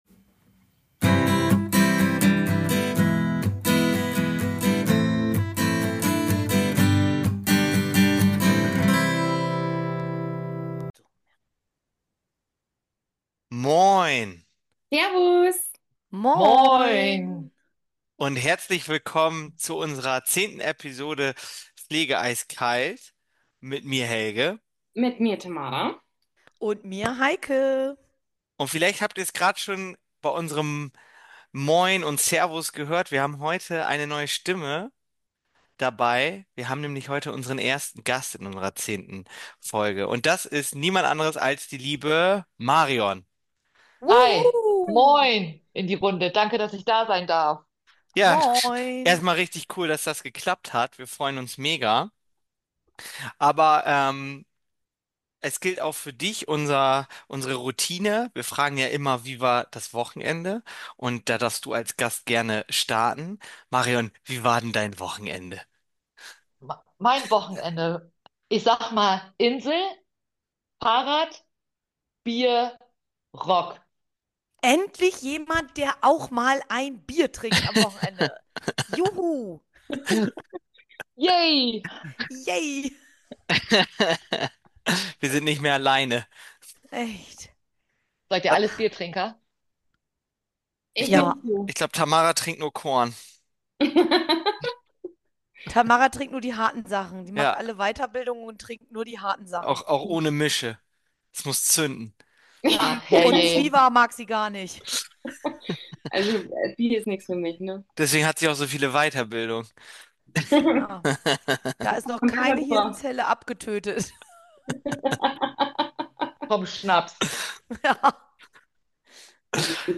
In dieser Episode haben wir unseren ersten Gast am start.